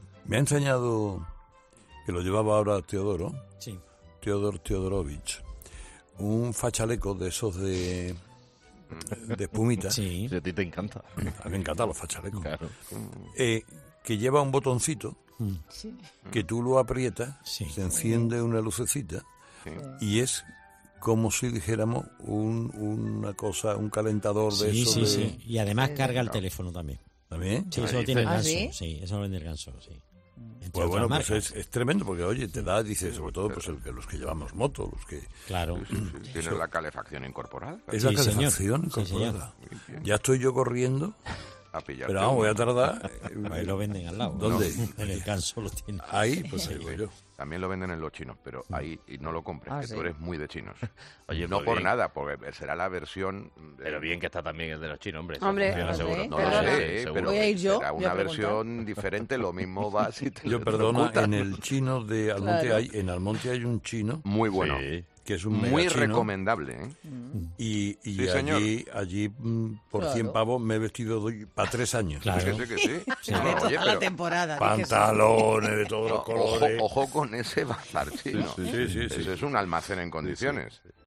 El comunicador no ha podido ocultar su sorpresa cuando un colaborador de 'Herrera en COPE' ha aparecido en el estudio con ella puesta
En este momento, se escuchaba en el estudio un comentario diciendo: "También lo venden en los chinos, pero ahí no lo compres, que tú eres muy de chinos", que ha generado un debate en torno a los bazares y sus inmensas posibilidades a la hora de comprar en ellos.